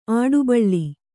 ♪ āḍubaḷḷi